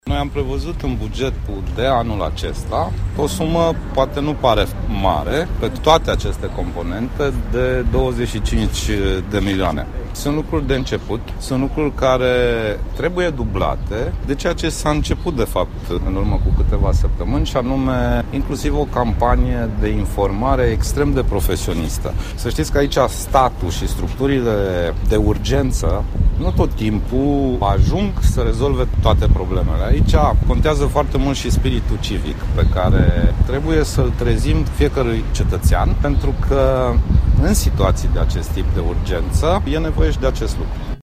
Aflat într-o vizită la Buzău, premierul Sorin Grindeanu a declarat, astăzi, că guvernul a alocat în buget 25 de milioane de lei pentru intervenţie la dezastre, dar că această sumă ar trebui dublată: